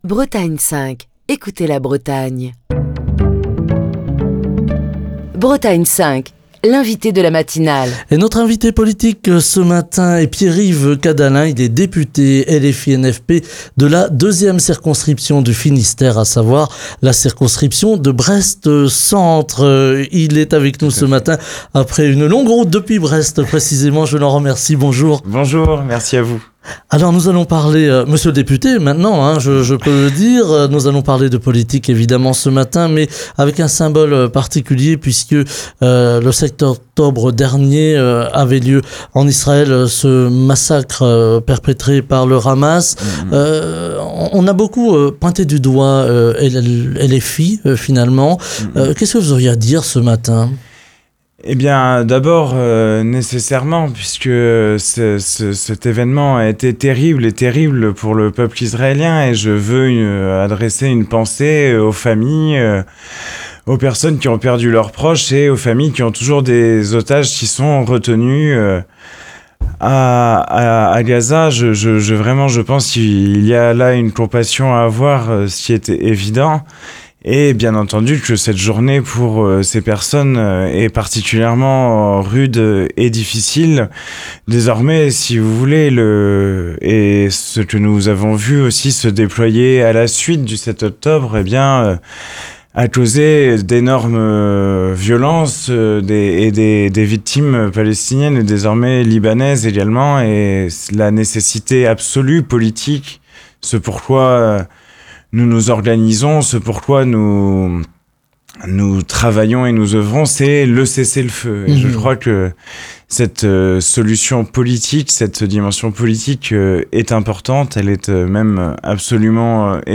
Ce lundi, Pierre-Yves Cadalen, député LFI-NFP de la deuxième circonscription du Finistère était l'invité politique de la matinale de Bretagne 5. Avant de commenter l'actualité politique et les grands sujets du moment, Pierre-Yves Cadalen a évoqué la guerre au Proche-Orient et salué la mémoire des victimes de l'attaque du Hamas contre Israël il y a un an, le 7 octobre 2023.